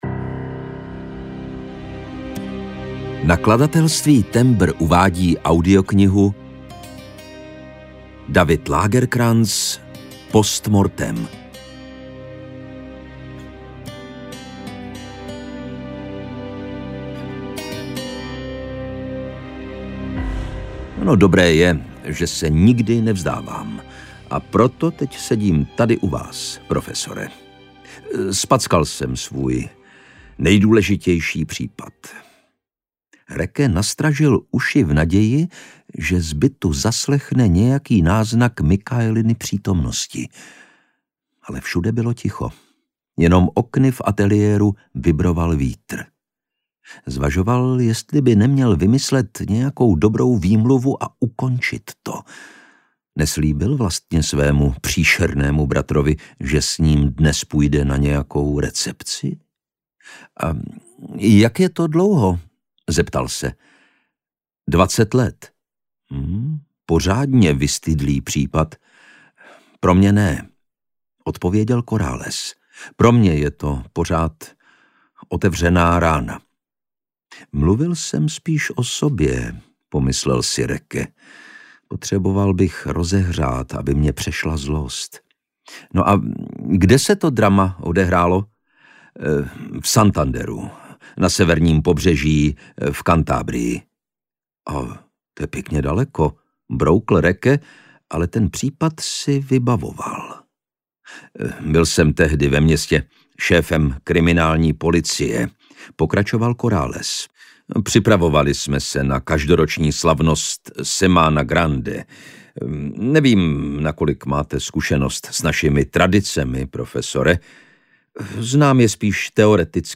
Post mortem audiokniha
• InterpretLukáš Hlavica